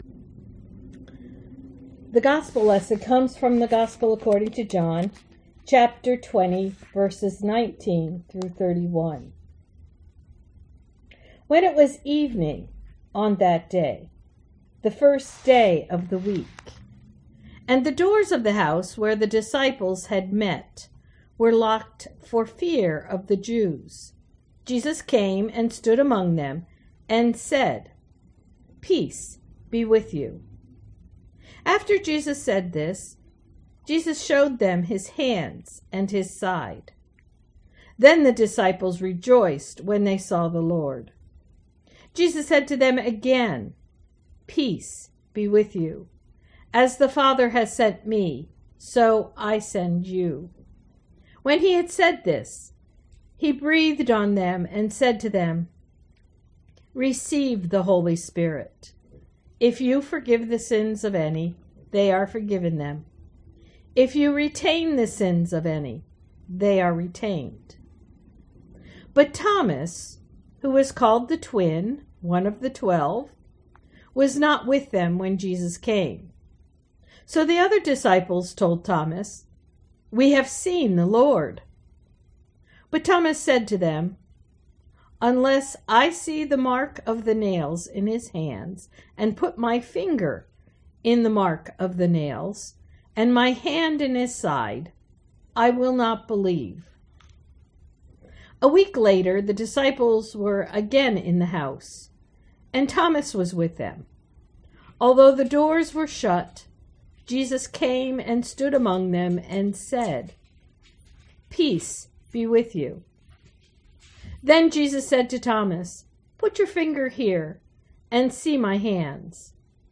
Gospel Reading: